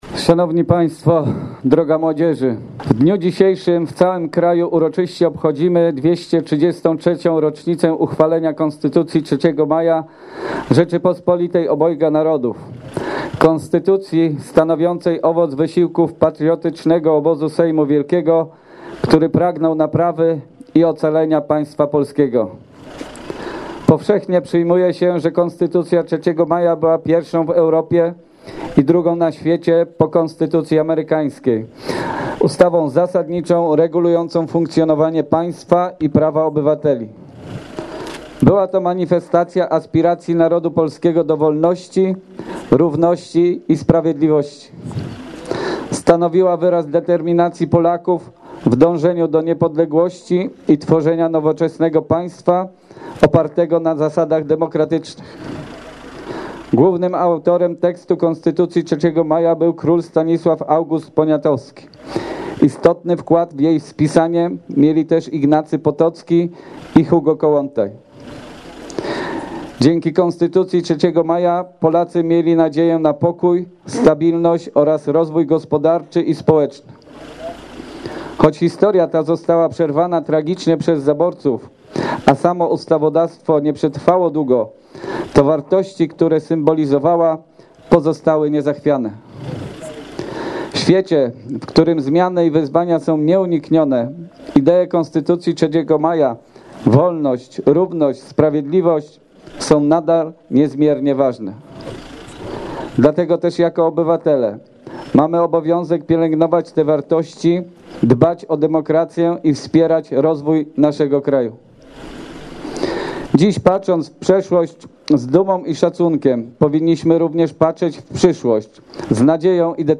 Tam odśpiewano Mazurka Dąbrowskiego, złożono kwiaty, a burmistrz miasta, Waldemar Stupałkowski wygłosił okolicznościowe przemówienie: